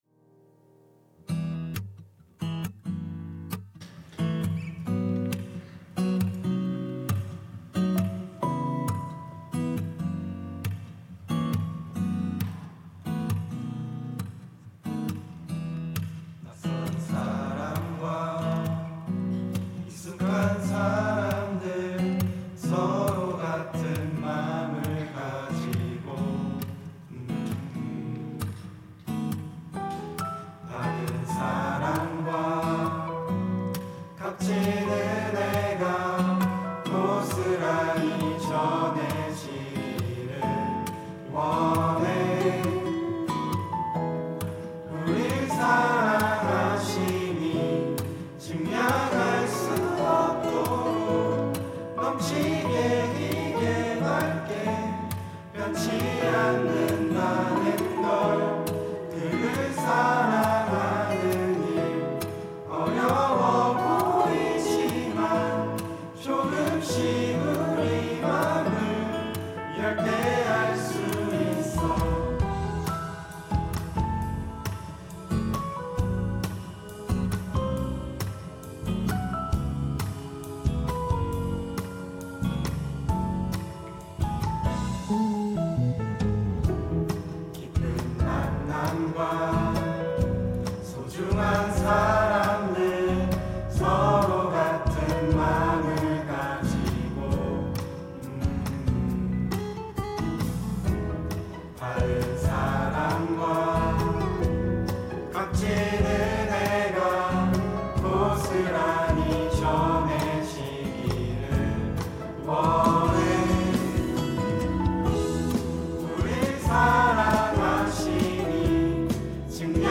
특송과 특주 - 같은 마음